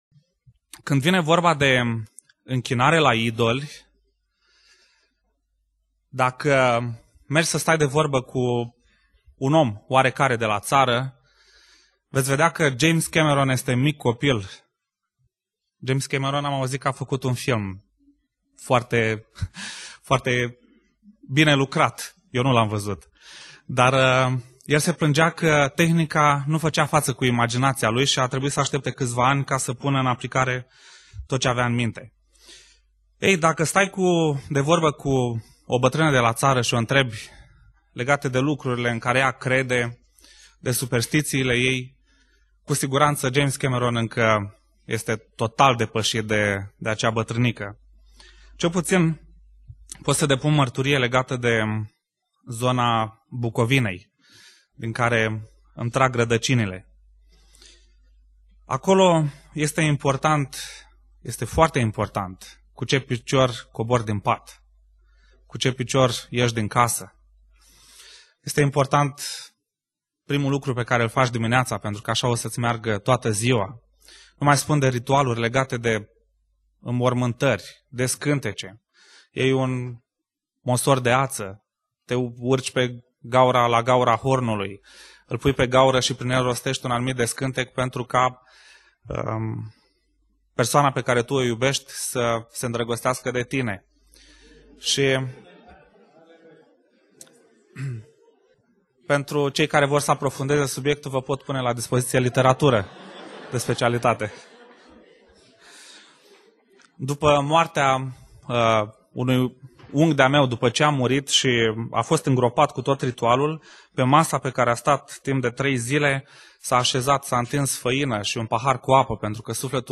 Predica Aplicatie - Isaia 43-44